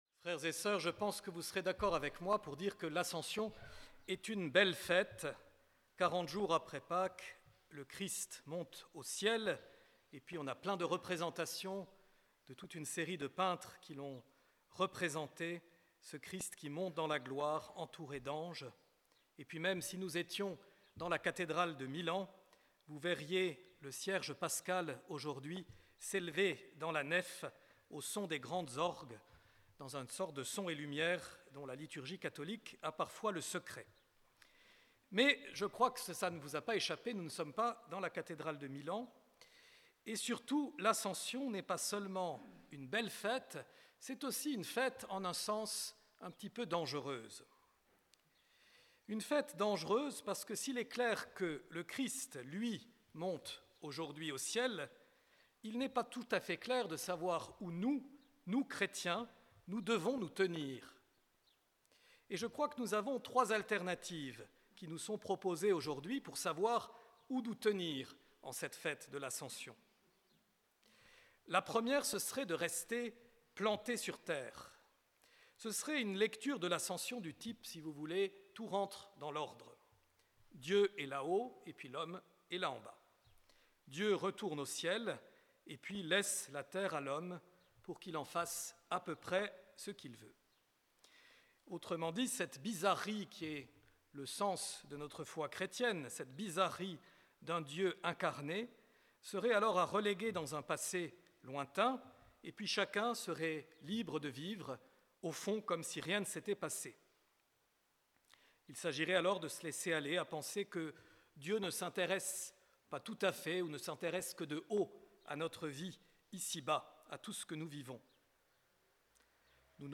Enregistrement : l'homélie